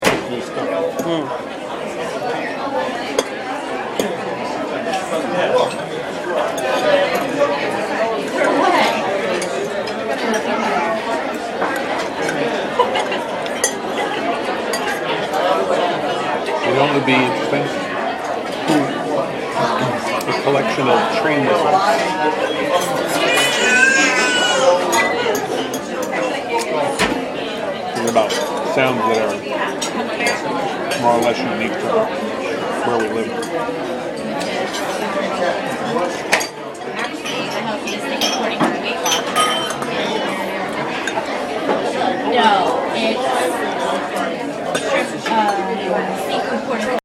silverware on plates – Hofstra Drama 20 – Sound for the Theatre
Location: Seafood Restaurant Carle Place New York
Location: Seafood Restaurant Carle Place New York Sounds heard: woman laughing across the room scrapping of forks across the plate clinking of wine glasses, as they are moved around on the table at the end there is cracking as a crab leg is split open thuds as plates are placed on the table
Diner-at-Olive-Garden.mp3